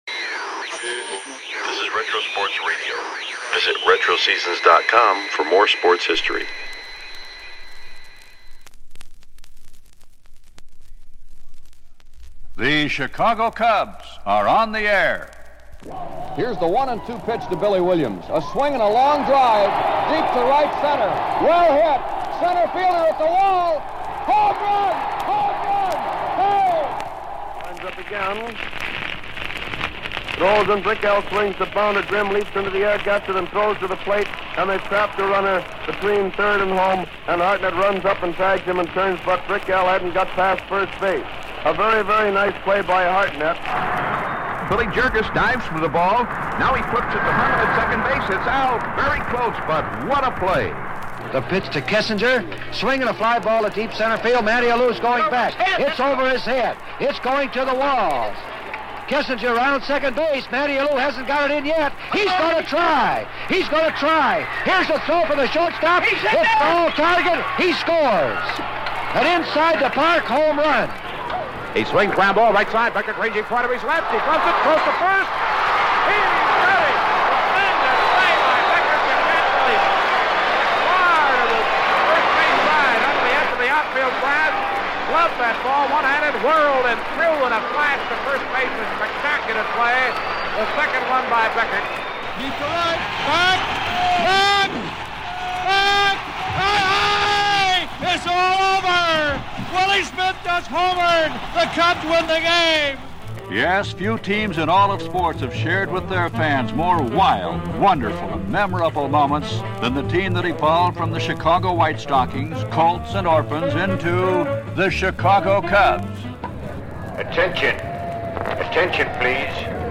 1983-May-12 - NHL Stanley Cup G2 - New York Islanders at Edmonton Oilers - Classic Hockey Radio Broadcast – Retro Sports Radio: Classic Games from History – Lyssna här